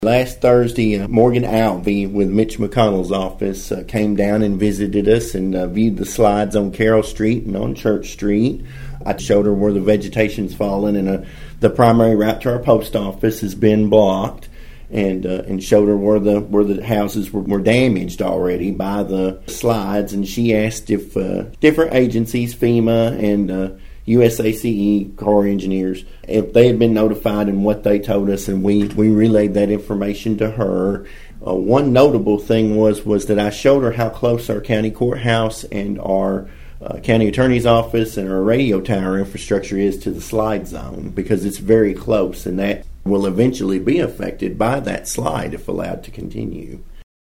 City Manager Robert Griggs told Thunderbolt News about a visit from Senator Mitch McConnel’s office.(AUDIO)